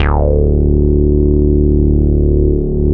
RESO BASS 2.wav